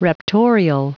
Prononciation du mot raptorial en anglais (fichier audio)
Prononciation du mot : raptorial